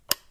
switch29.ogg